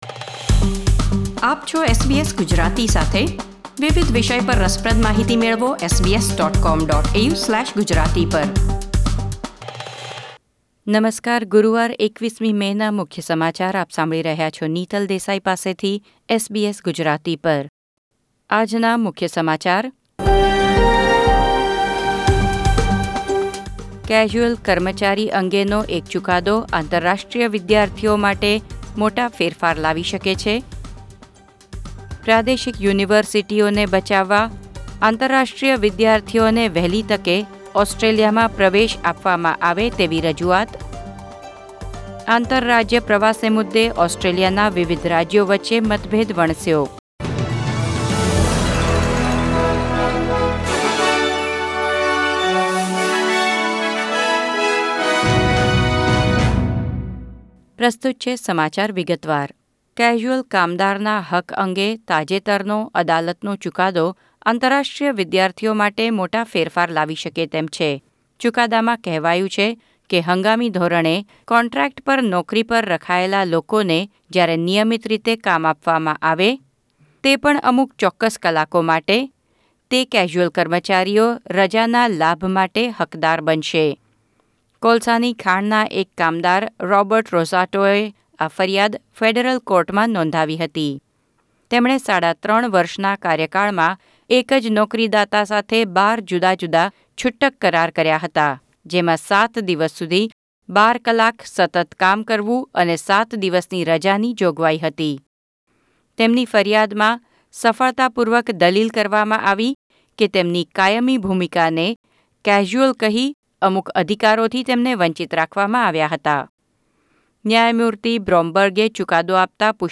SBS Gujarati News Bulletin 21 May 2020